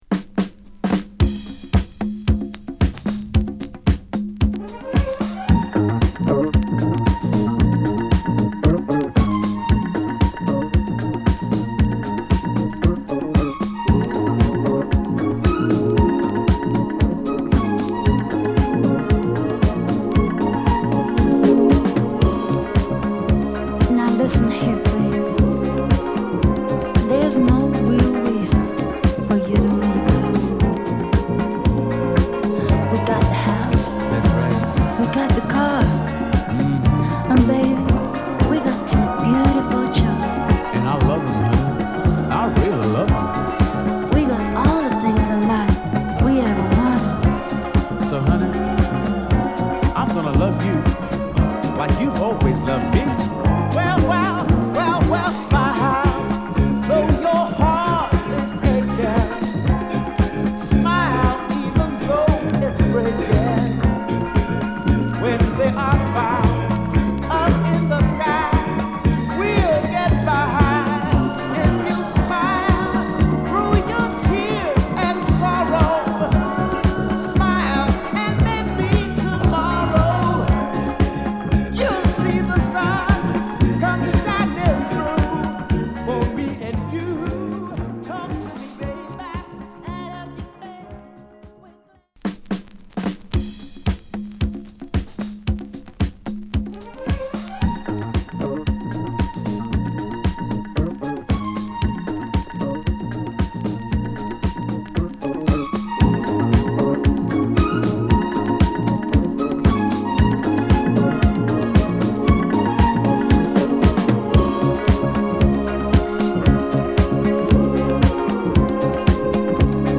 ナイス・ジャマイカン・ソウル／ディスコ！